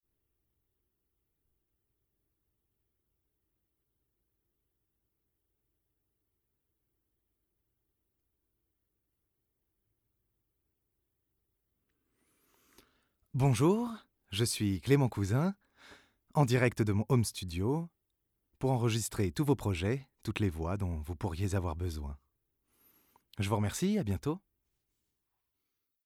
Male
20s, 30s, 40s
Bright, Confident, Cool, Corporate, Engaging, Friendly, Smooth, Soft, Warm, Young
Voice reels
Microphone: U87-A